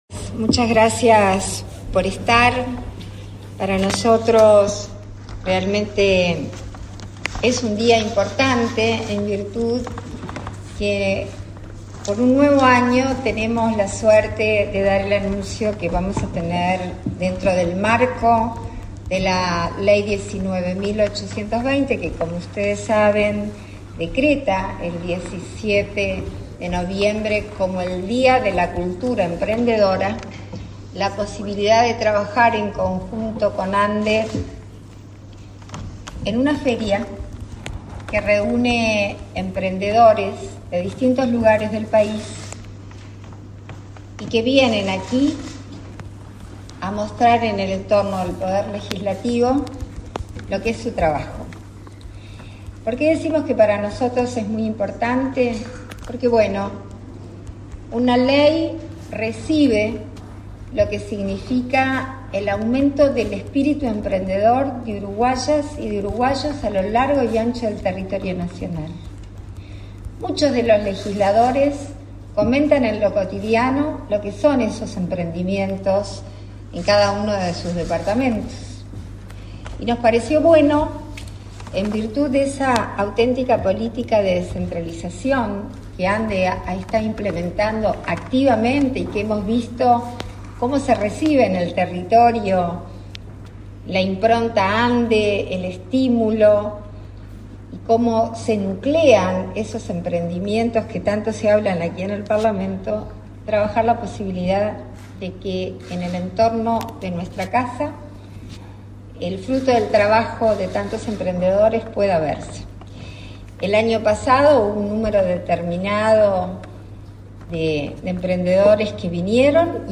Conferencia de prensa por el Día Nacional de la Cultura Emprendedora
Conferencia de prensa por el Día Nacional de la Cultura Emprendedora 17/11/2022 Compartir Facebook X Copiar enlace WhatsApp LinkedIn La Agencia Nacional de Desarrollo (ANDE) y el Parlamento celebraron, este 17 de noviembre, el Día Nacional de la Cultura Emprendedora, con la segunda edición del Espacio Emprendedor. Participaron en el evento la vicepresidenta de la República, Beatriz Argimón, y la presidenta de la ANDE, Carmen Sánchez.